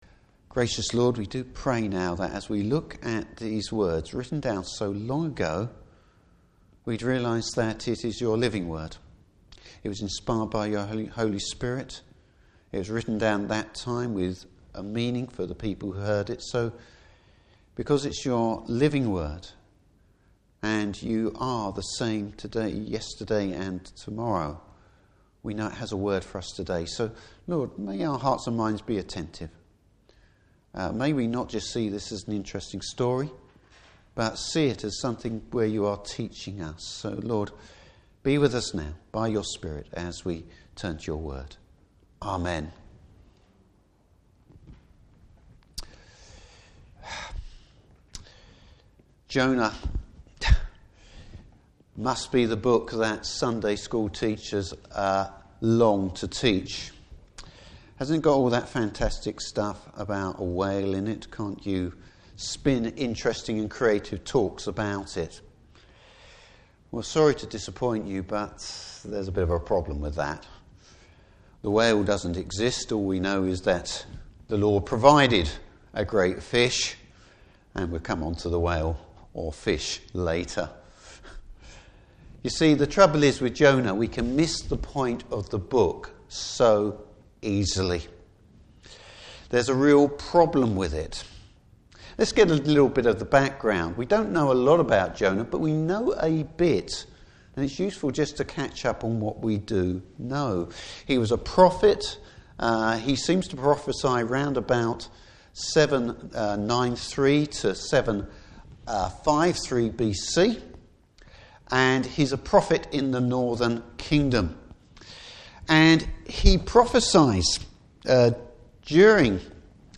Service Type: Evening Service Introducing the one of the most reluctant prophets in the Bible!